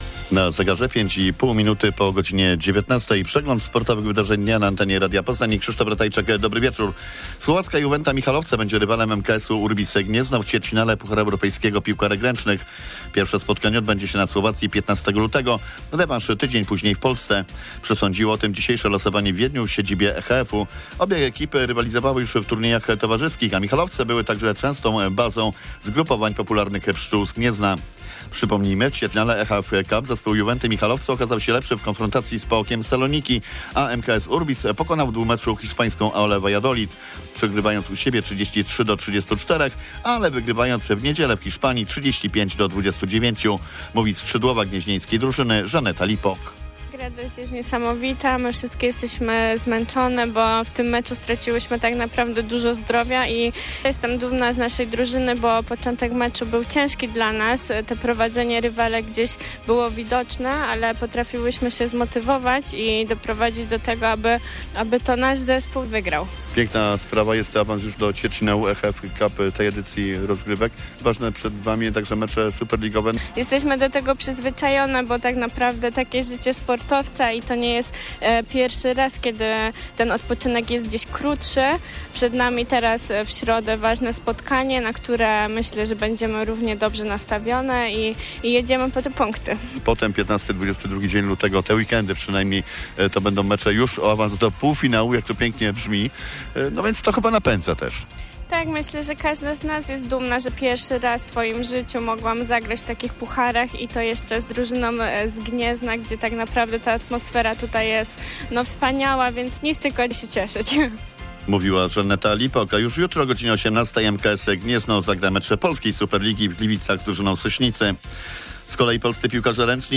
21.01.2025 SERWIS SPORTOWY GODZ. 19:05